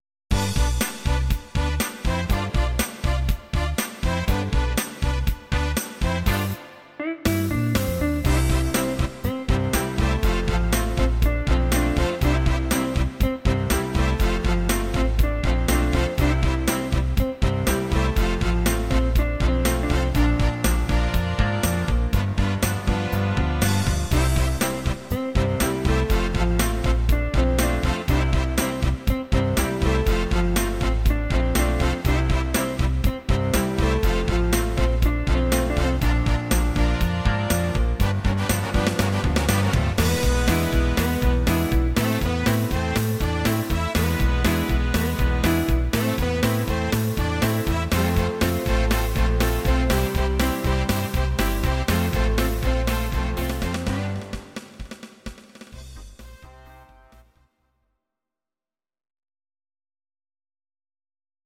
Please note: no vocals and no karaoke included.